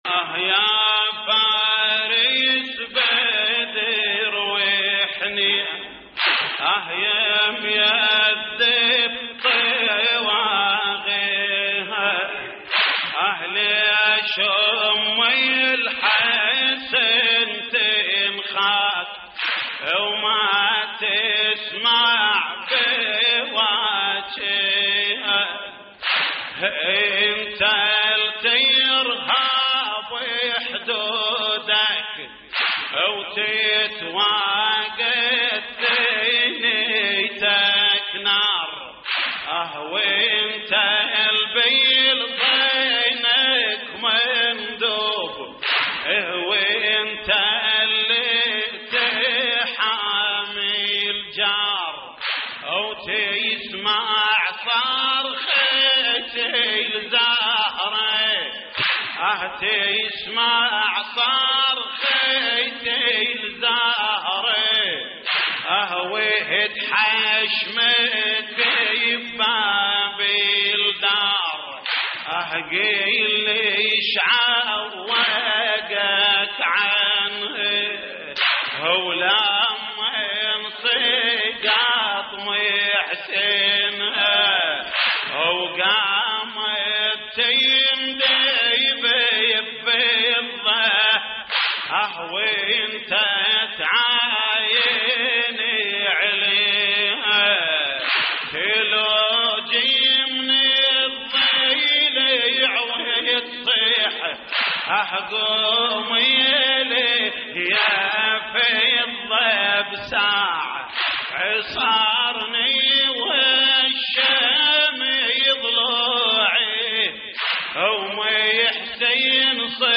تحميل : يا فارس بدر وحنين يميدب طواغيها ليش ام الحسن تنخاك وما تسمع بواكيها / الرادود جليل الكربلائي / اللطميات الحسينية / موقع يا حسين